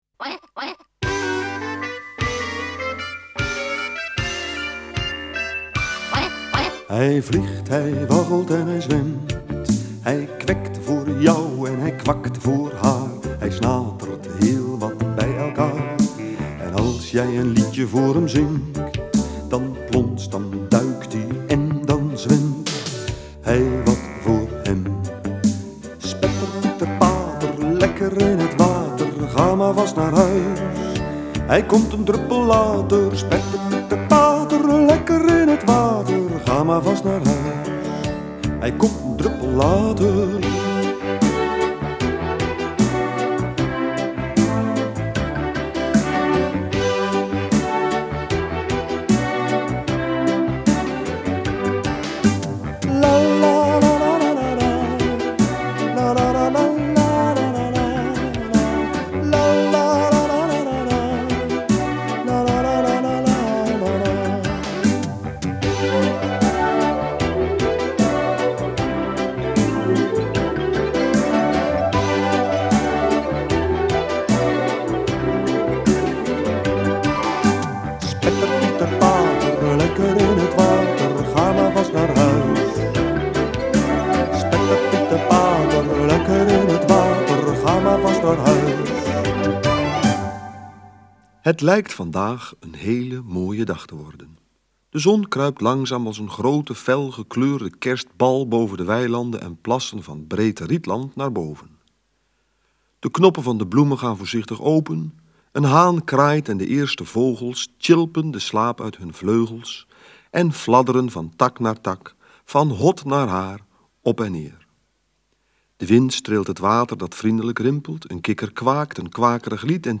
Hier kunt u luisteren naar het “voorleesverhaal” van de eerste aflevering.